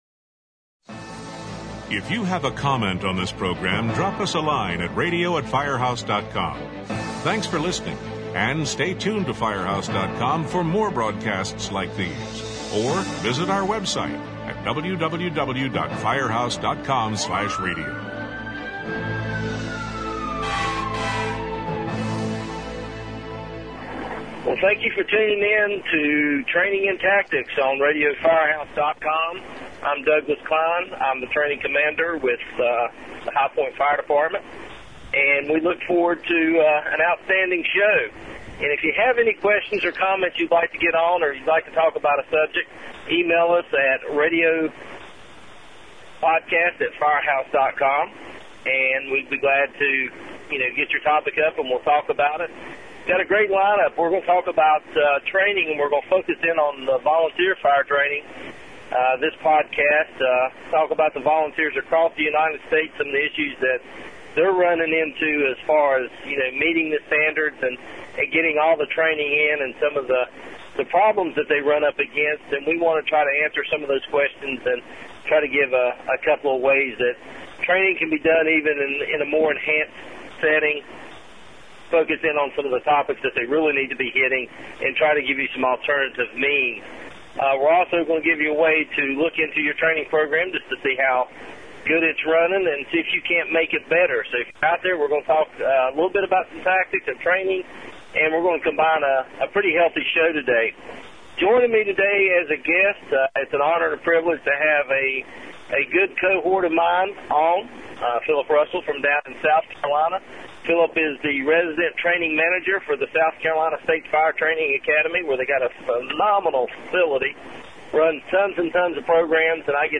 These two fire service veterans talk about the importance of making drills interesting and challenging. They also talk about a needs assessment and gap analysis to determine the direction the training needs to go.